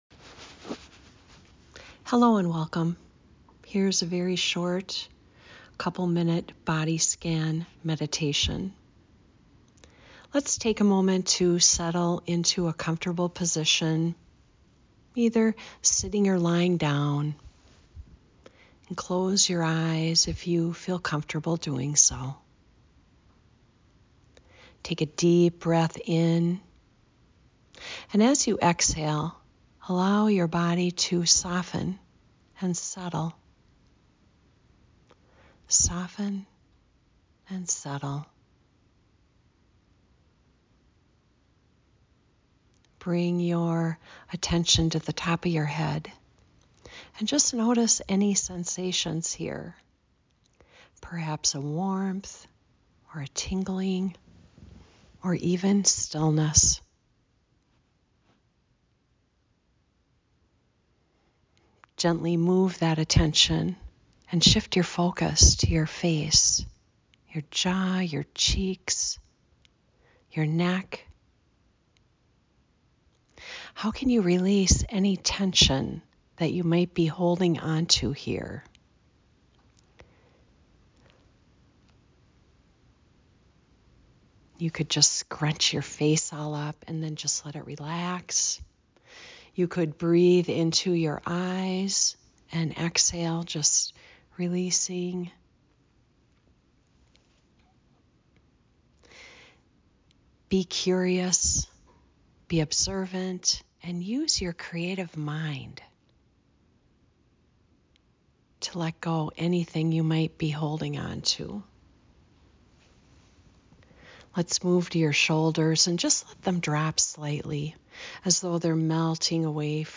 A Mini Body Scan Meditation -